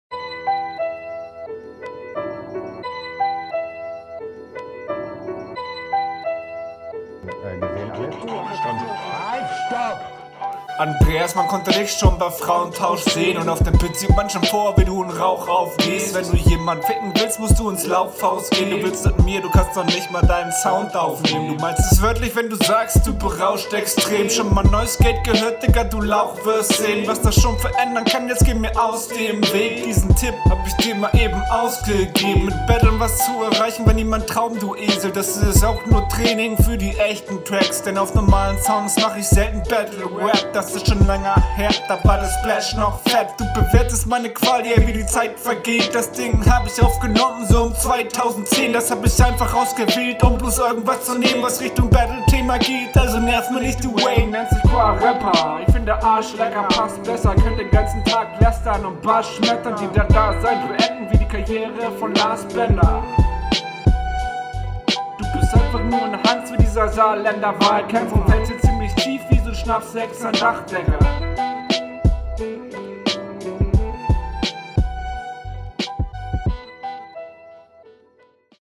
flow irgendwie angestrengter vom gegner hier und da n timing fehler aber auch nicht super …